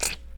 terumet_squish_place.0.ogg